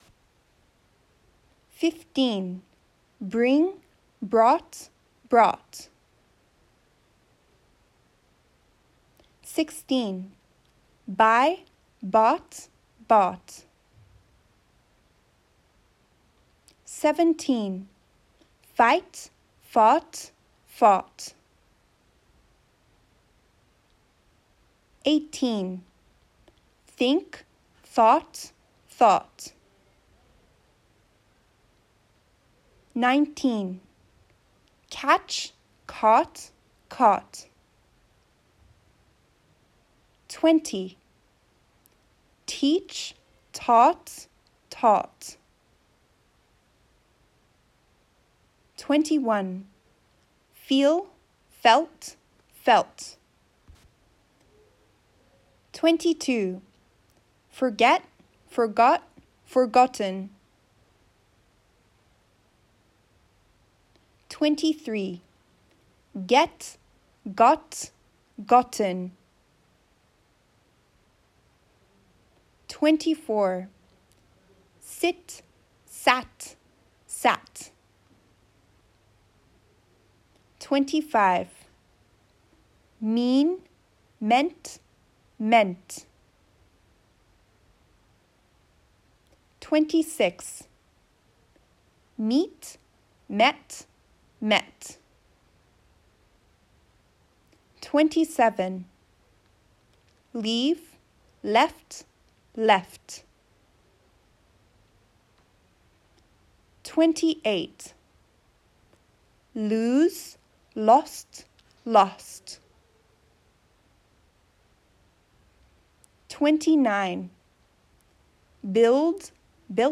覚えるコツは、何度も聞いて、後に続けて練習することです。